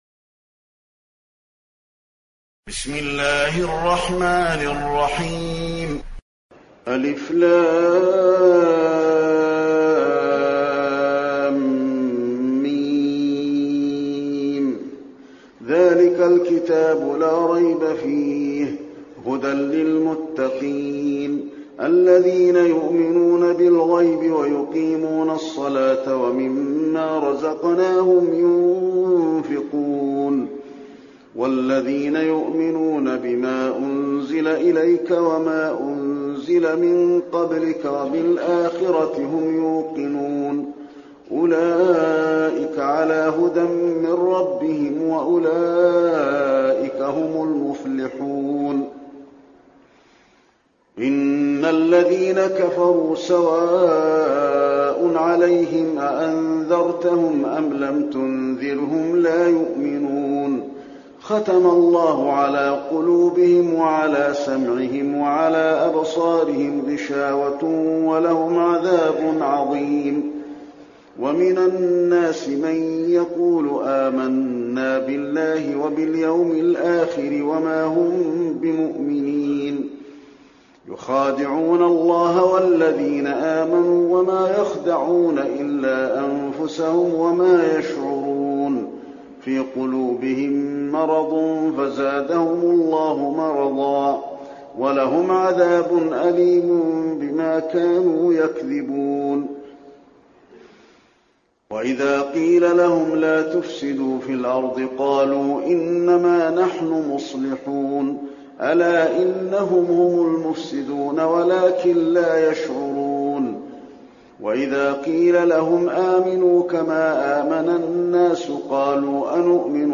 المكان: المسجد النبوي البقرة